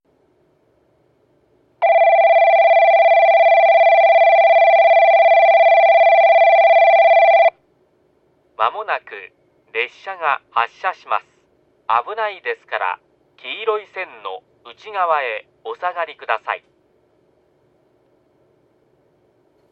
スピーカー 接近表示機/ユニペックスラッパ
発車ベル
ベルスイッチはワンプッシュ式の為鳴動時間は固定です。午前中の金沢方面の列車で収録しました。